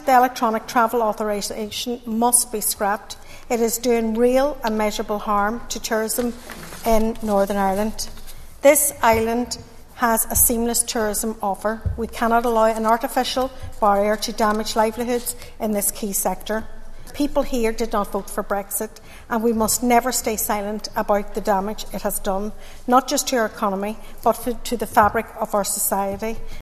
Speaking following the first EU/UK summit since Brexit, Foyle MLA Sinead McLaughlin said there is an integrated tourism offering on the island, and that’s being compromised by the ETA.